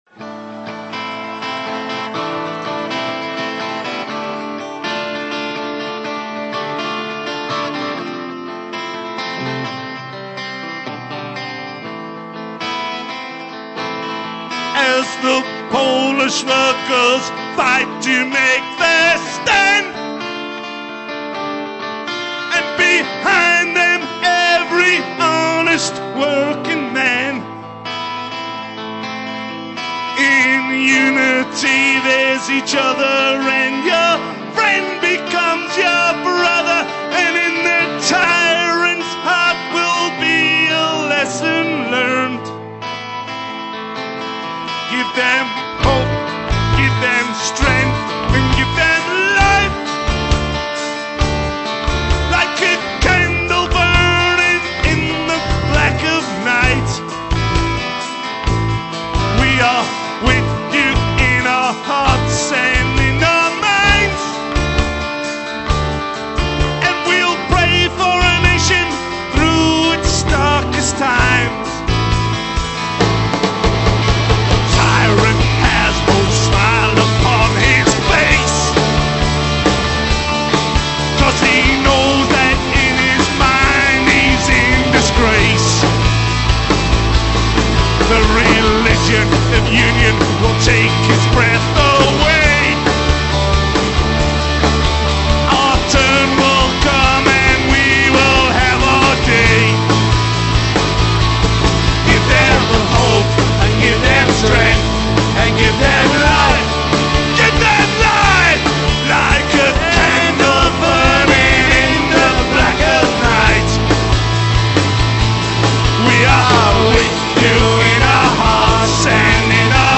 Лирическая песня о пролетарской солидарности.